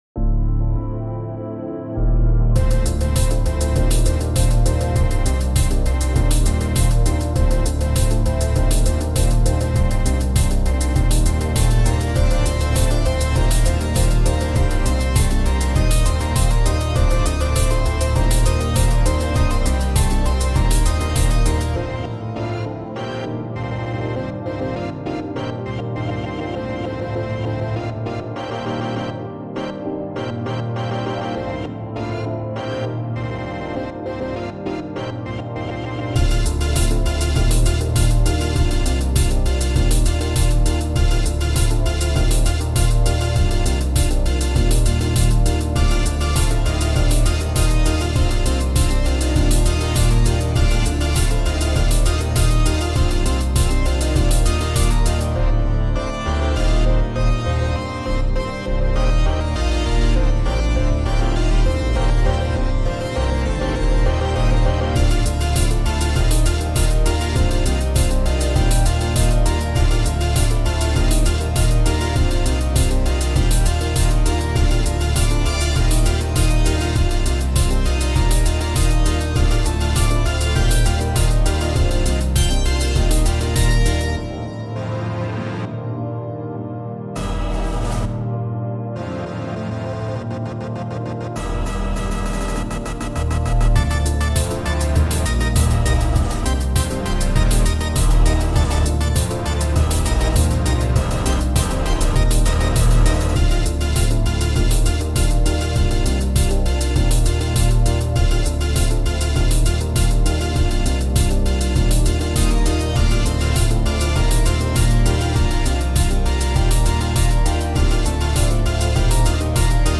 in 8D sound